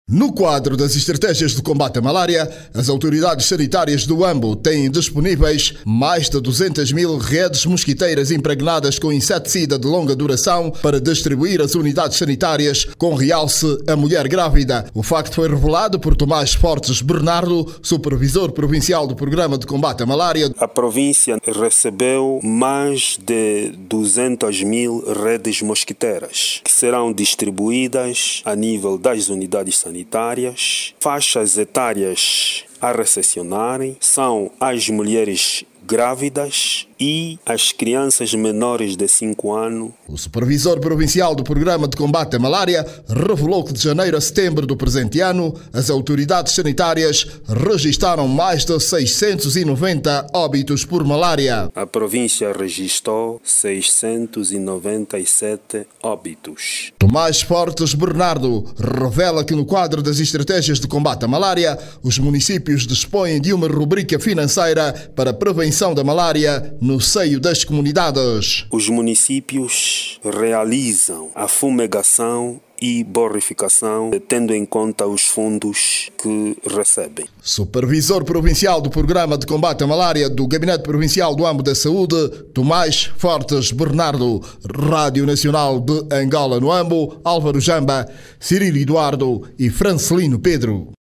HUAMBO-SOBE-MALARIA-EMISSAO-12-HRS-BOM.mp3